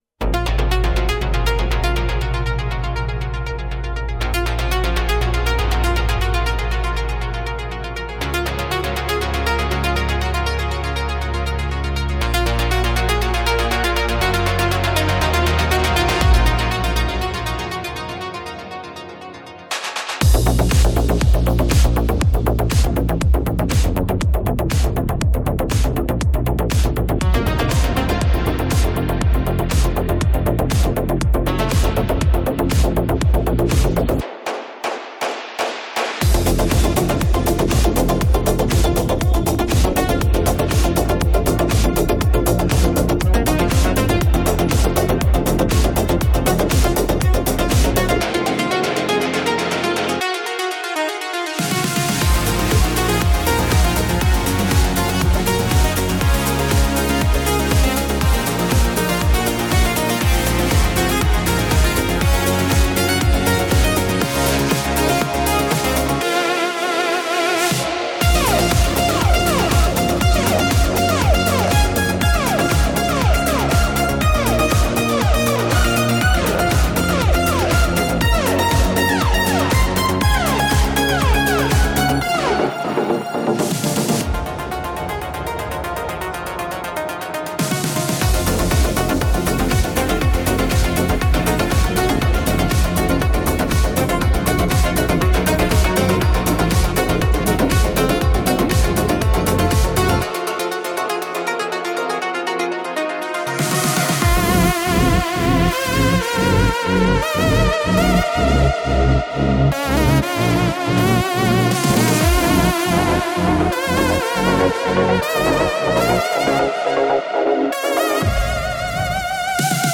Unpredictable. Kinetic. Precision in motion.
Independent electronic release